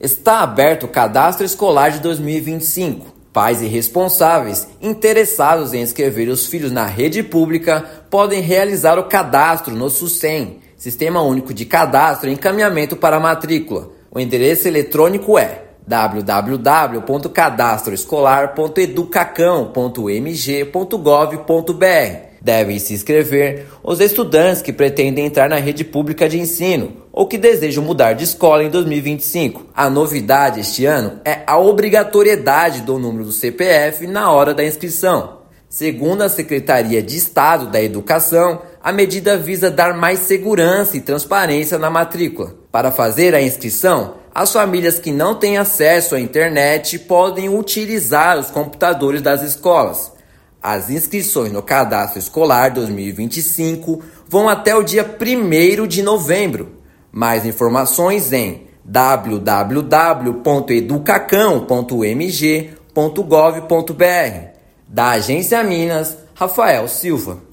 Inscrições podem ser realizadas até o dia 1/11 pelo Sistema Único de Cadastro e Encaminhamento para Matrícula. Ouça matéria de rádio.